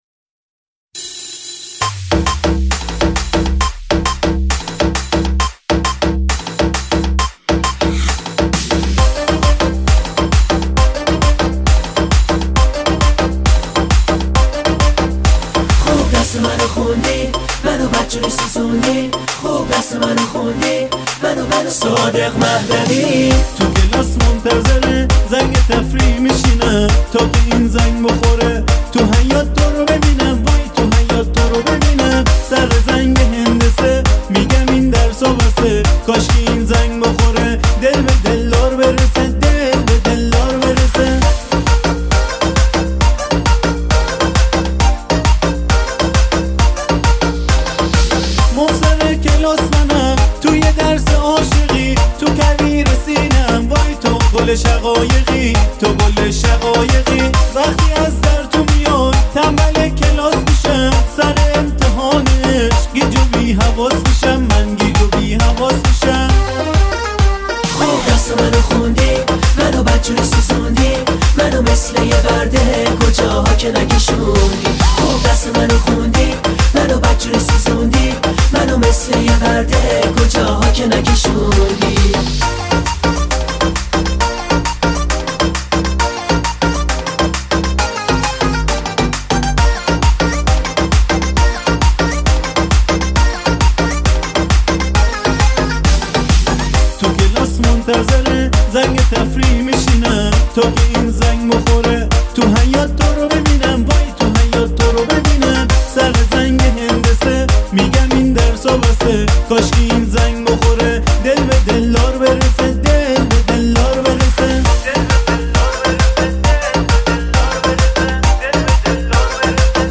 آهنگ های شاد ویژه شب یلدا